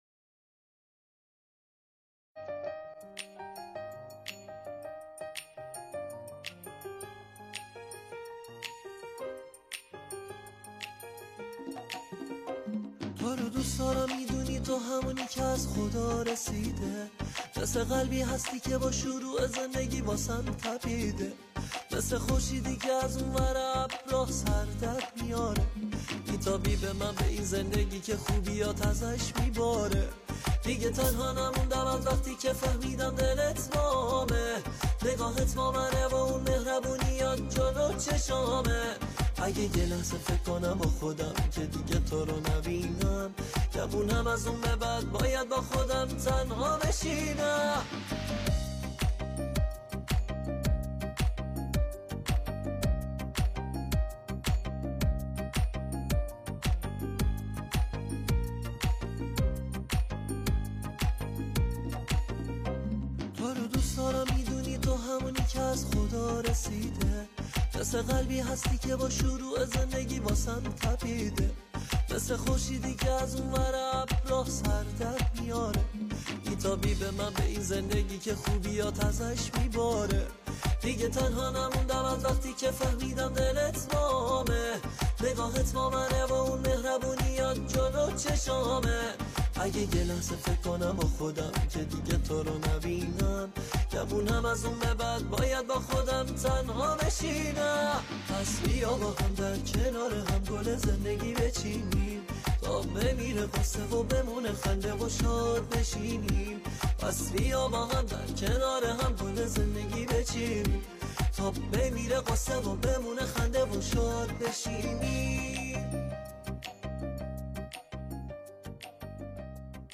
موضوع : آهنگ شاد , آهنگ فارســی ,